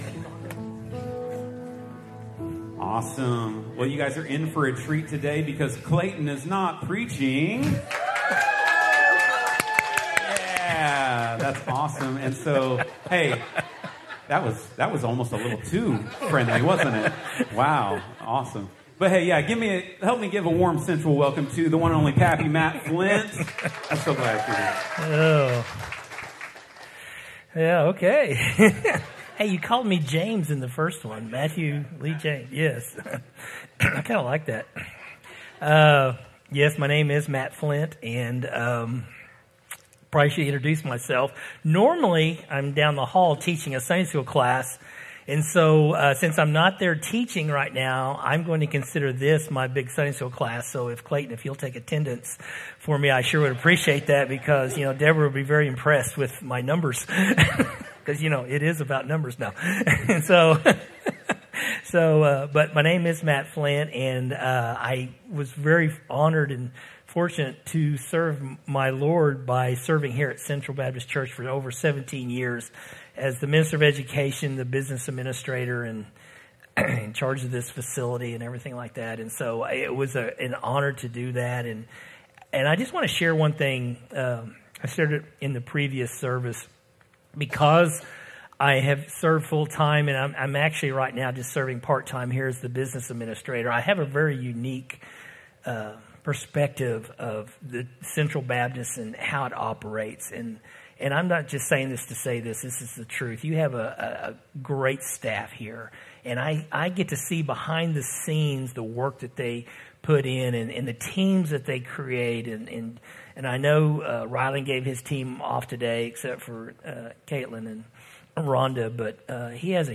From Series: "Sermons"